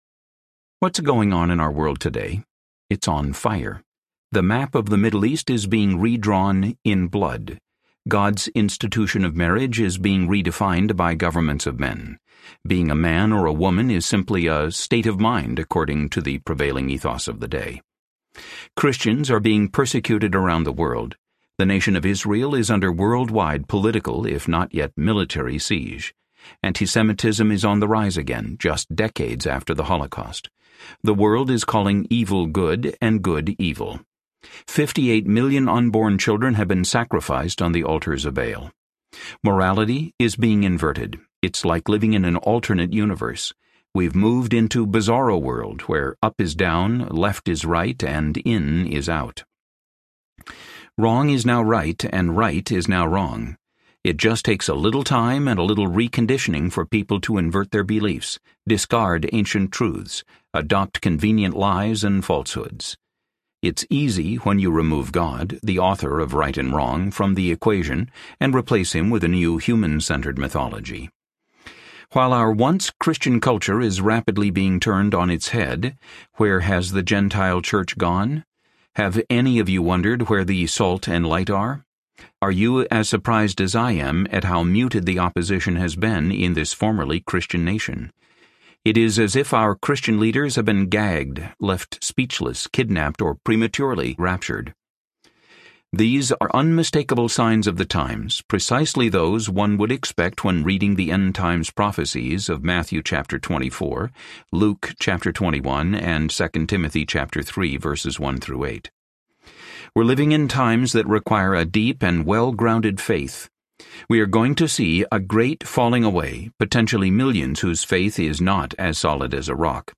The Restitution of All Things Audiobook
Narrator
7.8 Hrs. – Unabridged